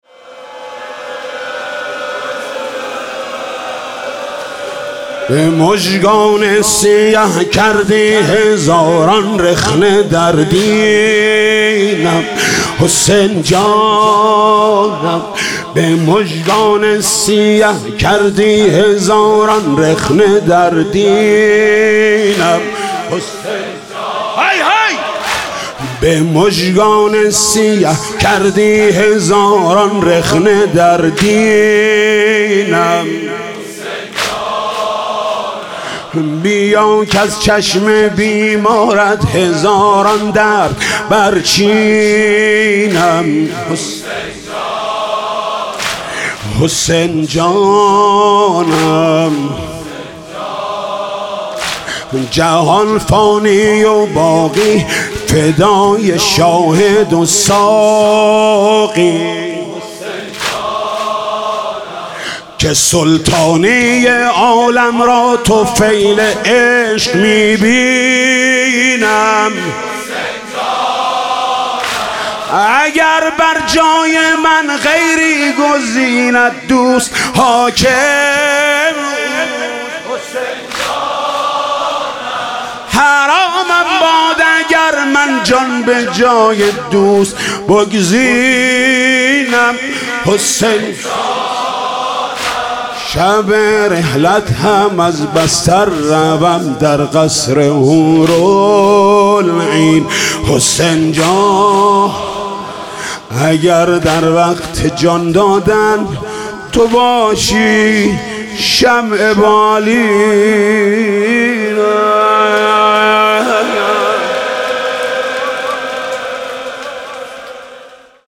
واحد شب سوم محرم آستان مقدس امامزاده علي اکبر